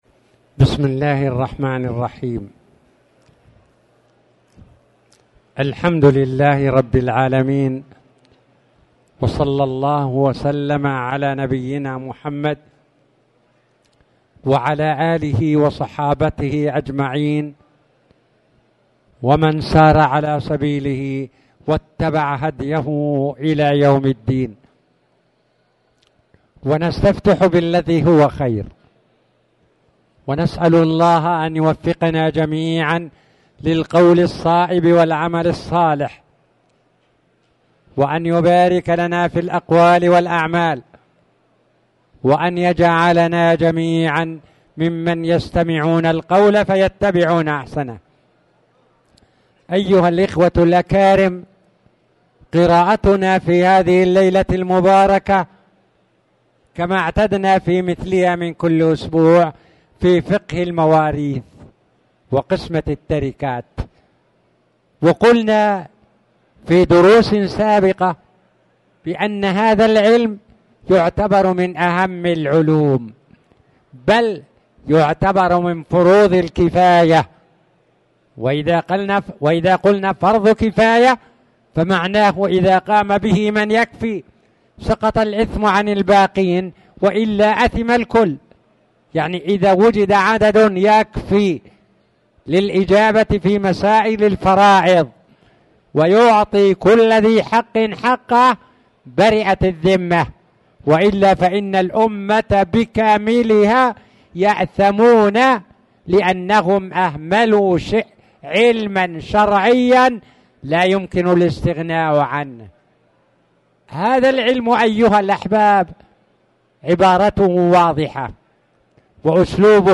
تاريخ النشر ١٦ شوال ١٤٣٨ هـ المكان: المسجد الحرام الشيخ